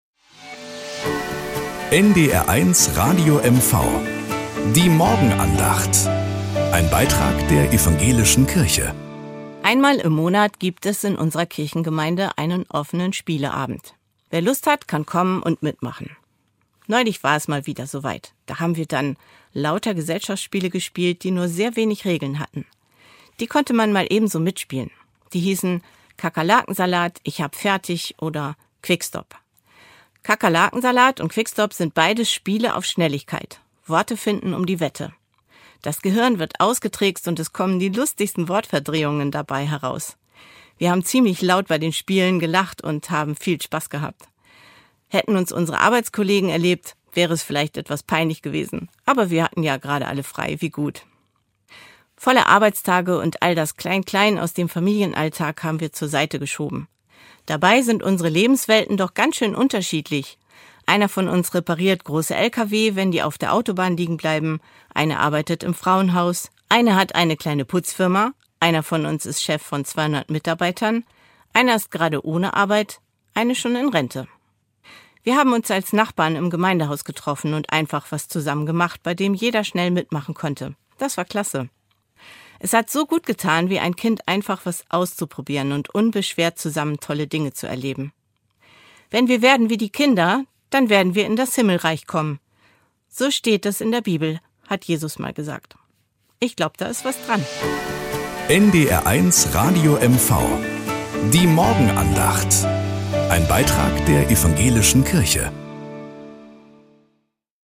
Morgenandacht.